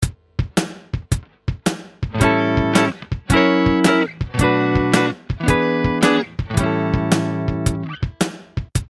One final blues rhythm element is to vary the chords used in the last 2 bars of the 12 bar structure.
Blues Turnaround 2 | Download
blues_turnaround2.mp3